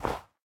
snow3.ogg